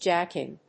アクセントjáck ín